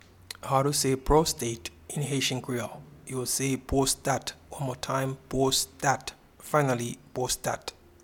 Pronunciation and Transcript:
Prostate-in-Haitian-Creole-Pwostat.mp3